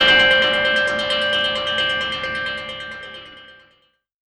GUITARFX 6-L.wav